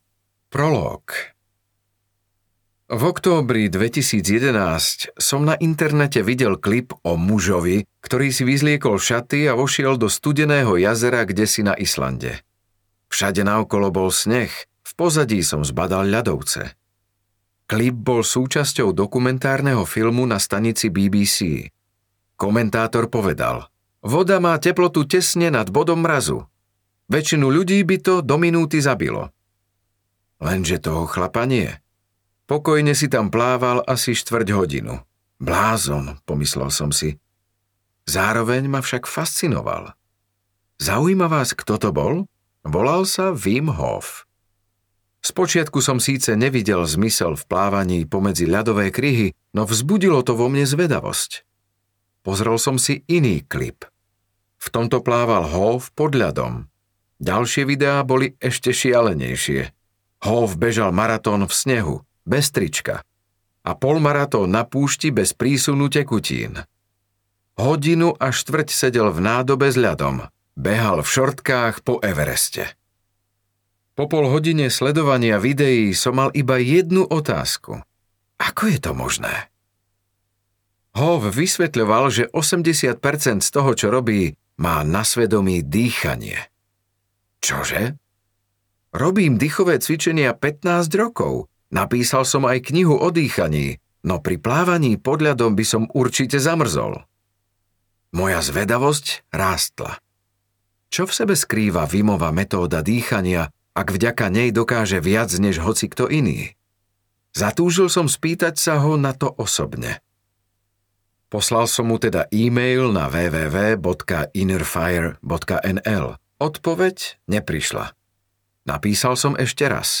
Cesta ľadového muža audiokniha
Ukázka z knihy
cesta-ladoveho-muza-audiokniha